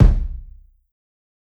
KICK_HEART.wav